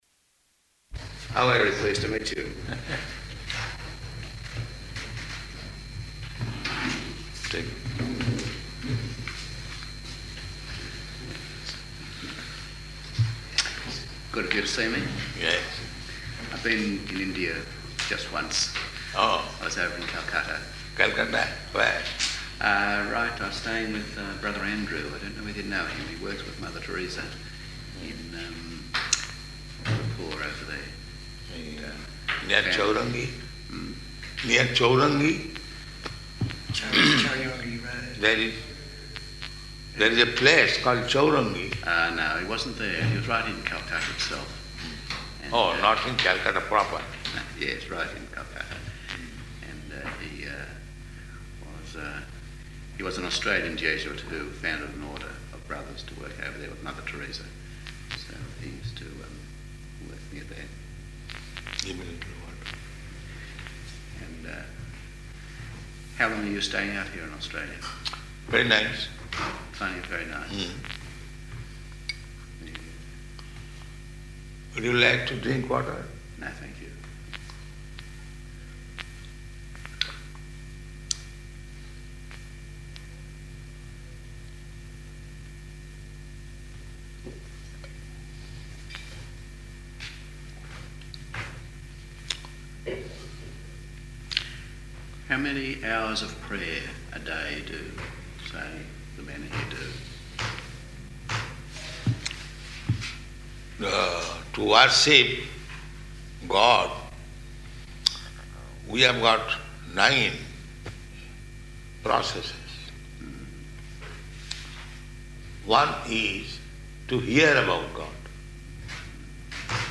Room Conversation with Jesuit